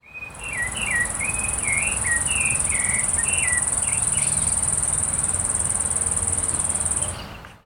Gilded Sapphire (Hylocharis chrysura)
Suele elegir la misma rama casi todos los días para ponerse a cantar.
Sex: Male
Condition: Wild
Certainty: Observed, Recorded vocal
Picaflor_bronceado.mp3